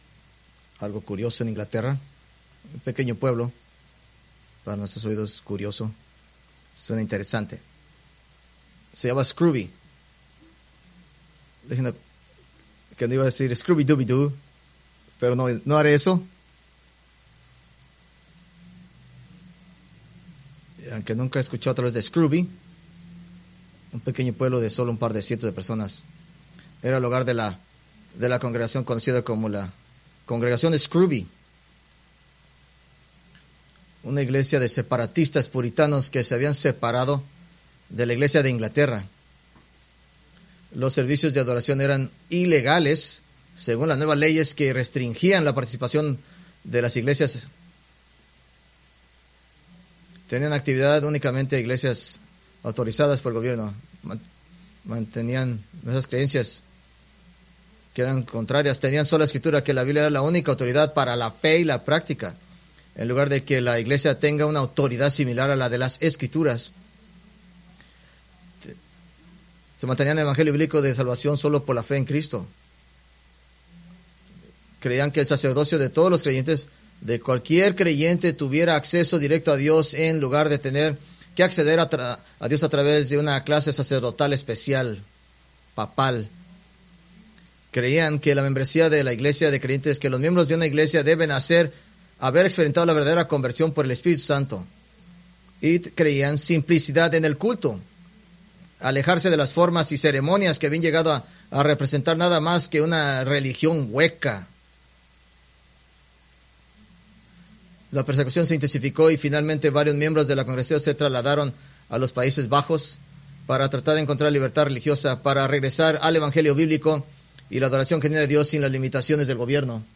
Preached November 24, 2024 from Escrituras seleccionadas